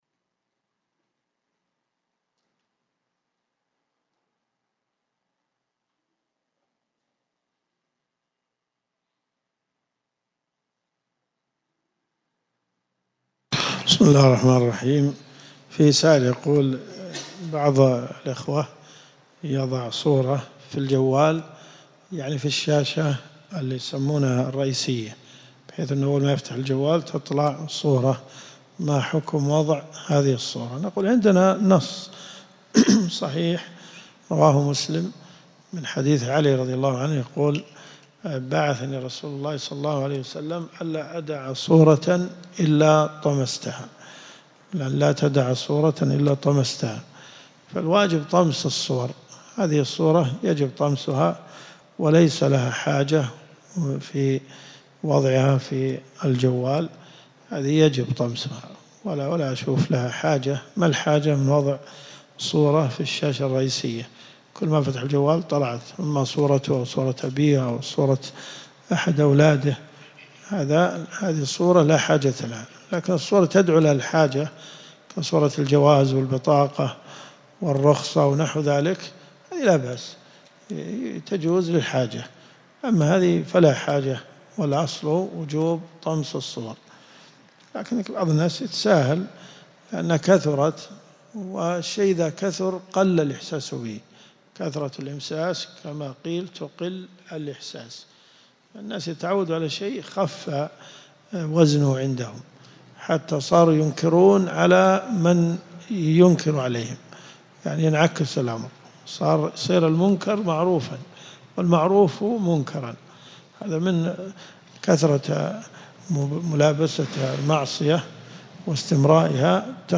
دروس صوتيه ومرئية تقام في جامع الحمدان بالرياض - فتاوى .
يبدء الدرس في الدقيقة 2.25 + الله + الرب + الرحمن الرحيم .